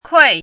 怎么读
kuì guì
kui4.mp3